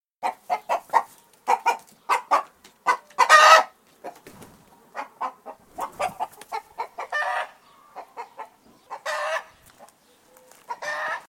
دانلود آهنگ مرغ از افکت صوتی انسان و موجودات زنده
جلوه های صوتی
دانلود صدای مرغ از ساعد نیوز با لینک مستقیم و کیفیت بالا